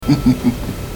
Evil Laughter 1 - ricochet